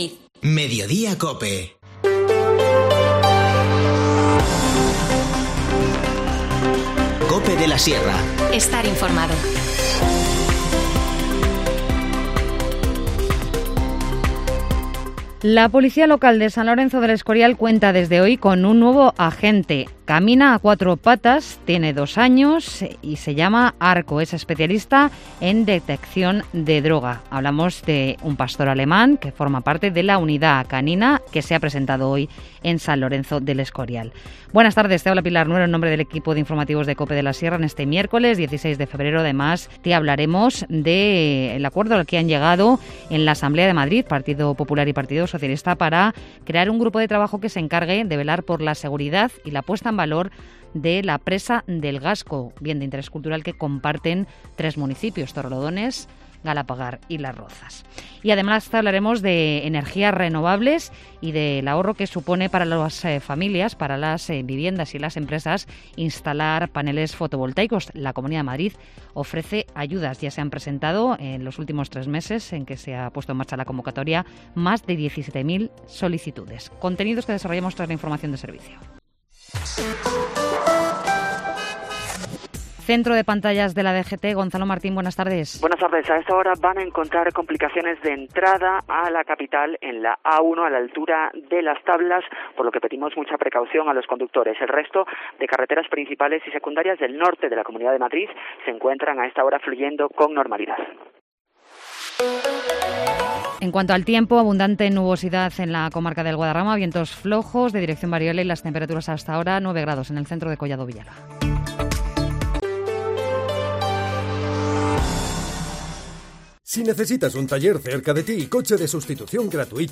Informativo Mediodía 16 febrero